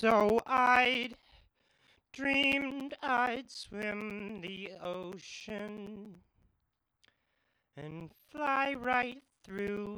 Sound quality issues when recording over pre existing tracks
And yeah, when I export it the weird graininess stays.
You have straightforward small “dropouts” it seems, where nothing is recorded.